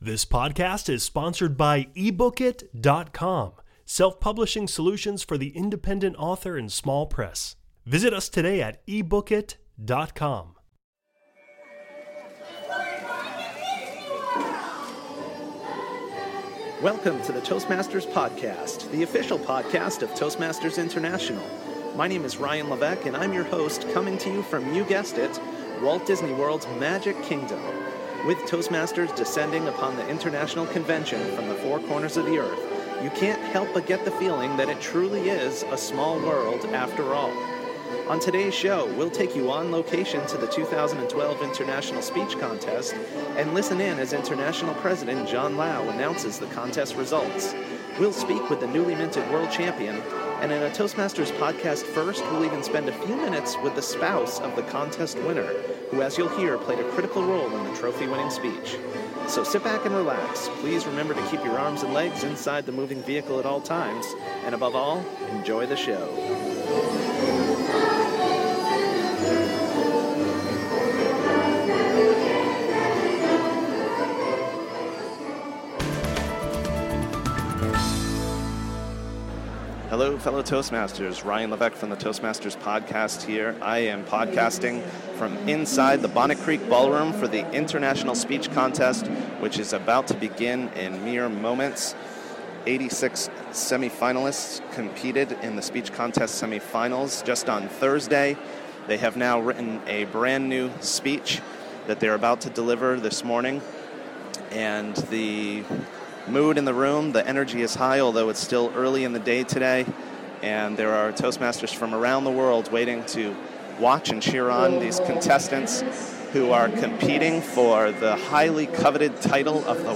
#062: 2012 International Convention - Live Interviews & WCPS | Toastmasters Podcast
[15:30] Go on location to the 2012 International Speech Contest at the International Convention in Orlando, Florida!